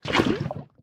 empty_lava1.ogg